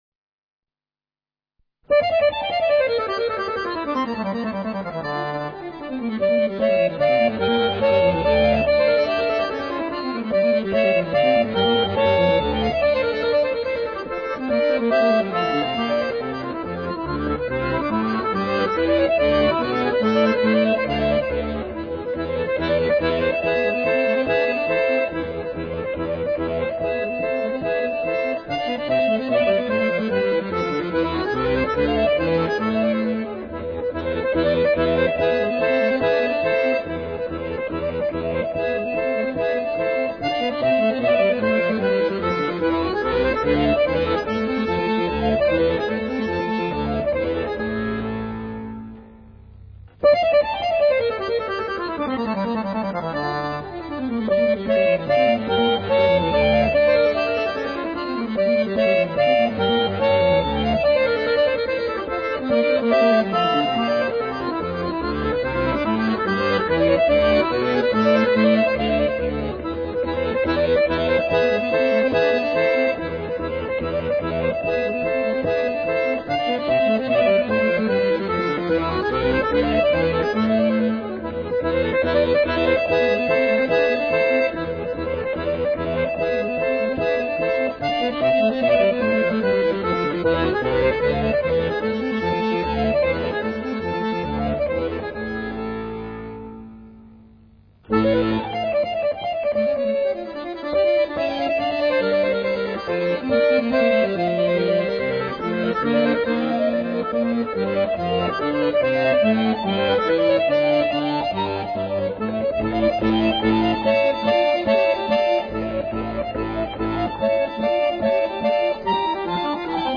Fisarmonica